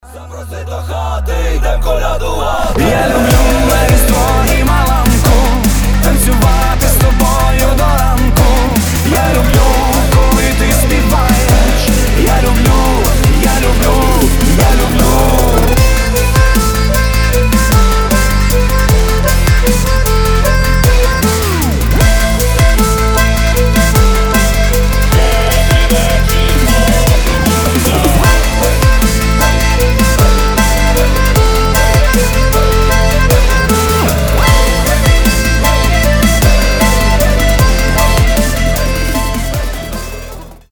скрипка
аккордеон
украинские
рождественские
фолк-рок